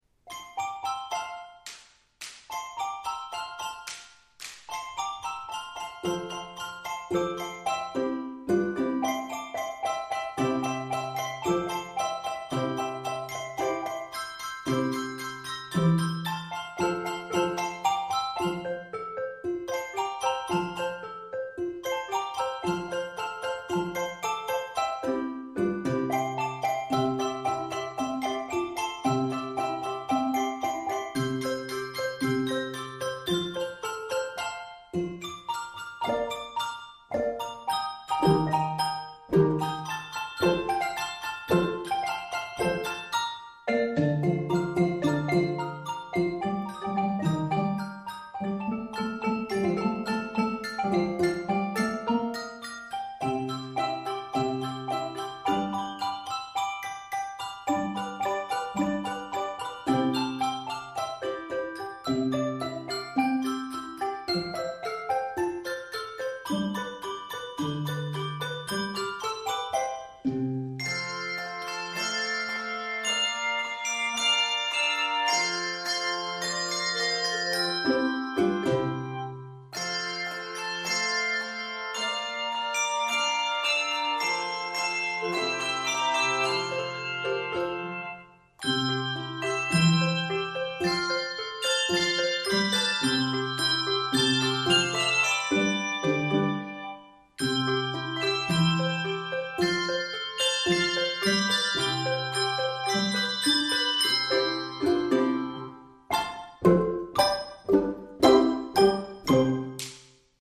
Staccato techniques are found throughout and add to the fun.
Begins in the key of F Major
then ends in C Major. 50 measures.